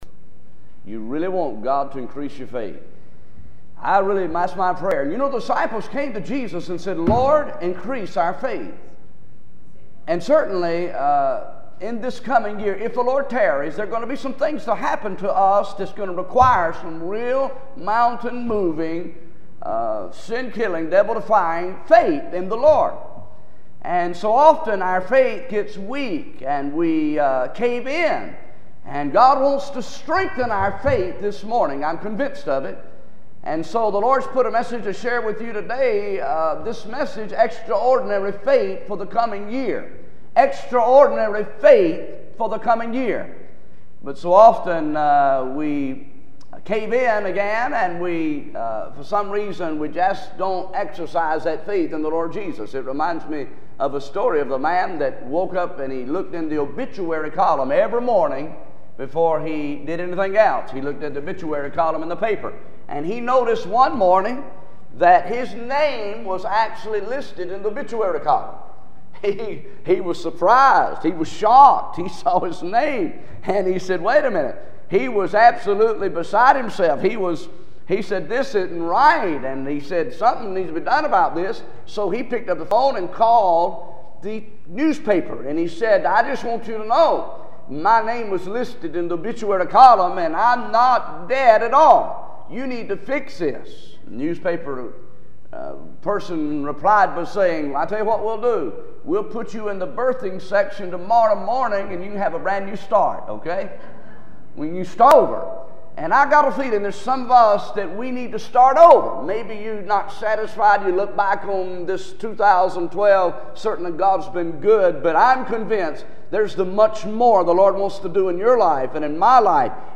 series: New Rocky Creek Sermon Series